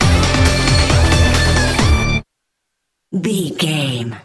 Aeolian/Minor
DOES THIS CLIP CONTAINS LYRICS OR HUMAN VOICE?
WHAT’S THE TEMPO OF THE CLIP?
hard rock
lead guitar
bass
drums
aggressive
energetic
intense
nu metal
alternative metal